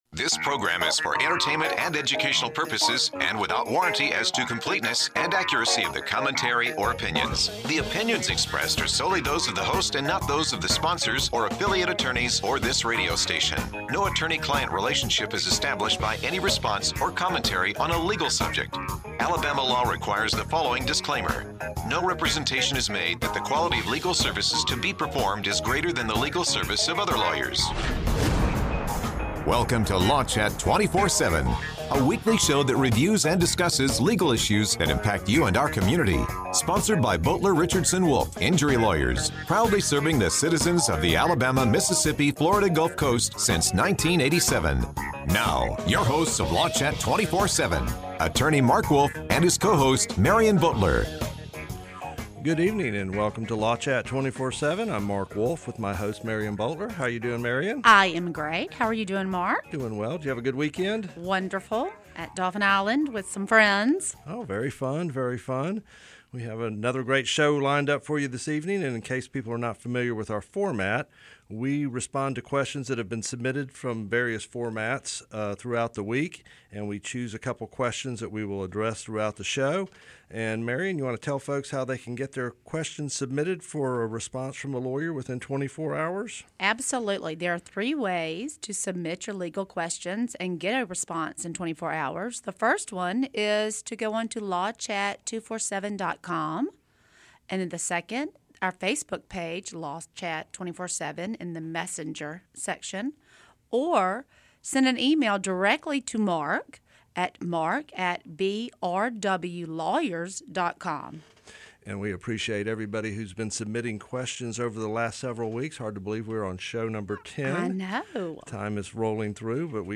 co-host an interactive presentation answering and discussing legal questions and topics of interest